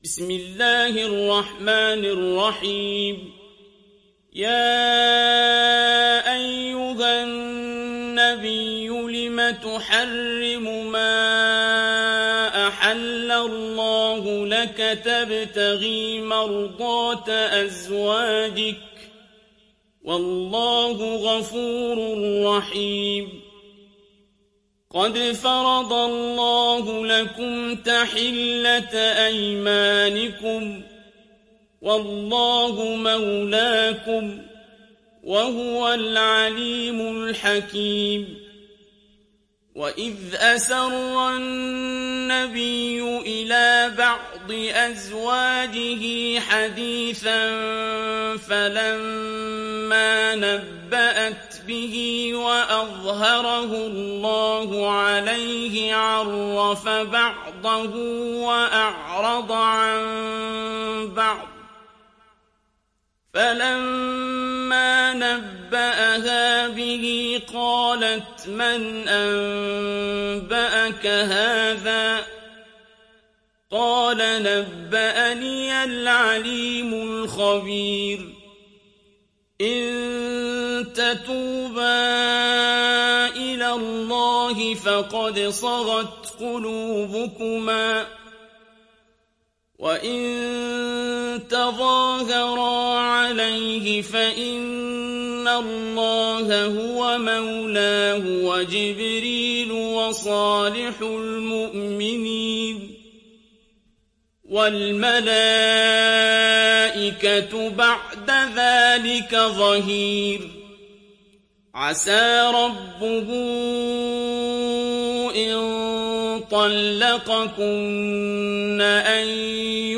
Sourate At Tahrim Télécharger mp3 Abdul Basit Abd Alsamad Riwayat Hafs an Assim, Téléchargez le Coran et écoutez les liens directs complets mp3